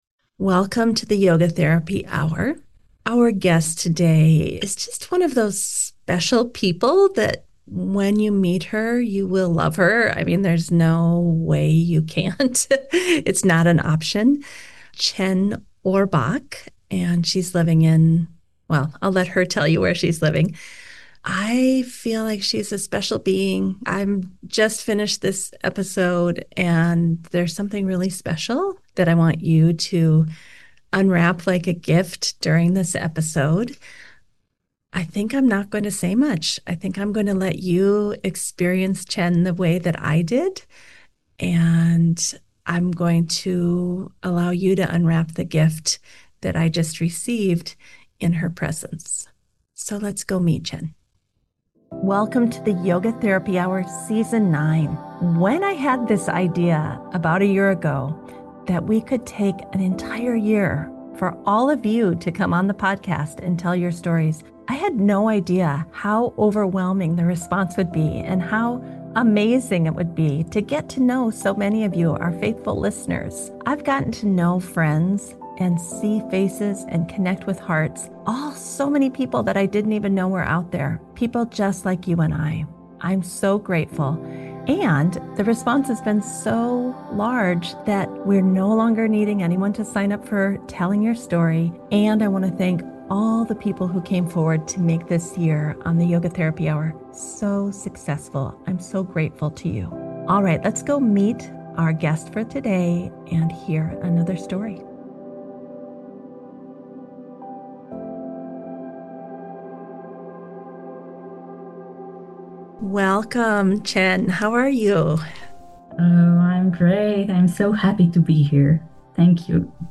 It’s a forward-looking conversation about bringing steadiness (sthira) and sweetness (sukha) into real life—mountain trails, laundry folding, and all.